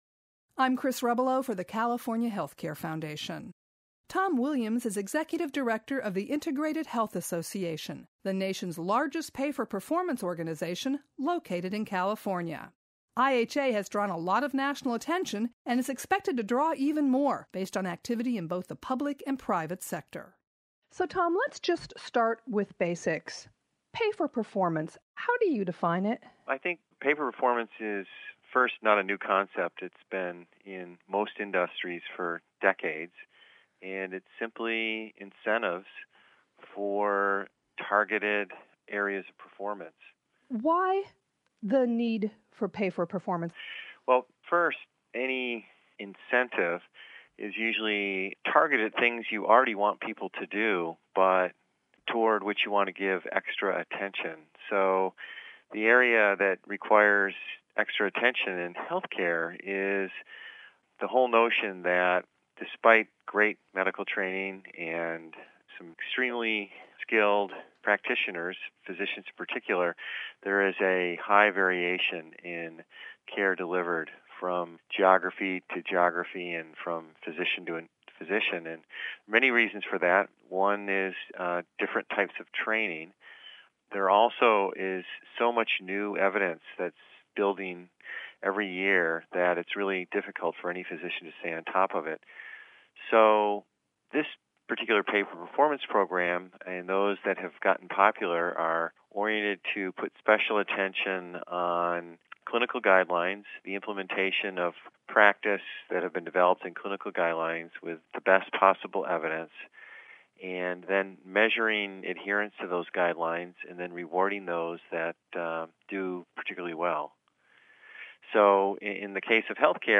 SPECIAL AUDIO REPORT: Government, Health IT Strengthen Pay-for-Performance Programs - California Healthline